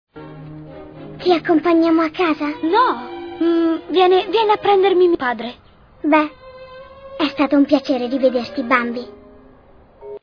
Il mondo dei doppiatori
Bambi 2", in cui doppia Faline.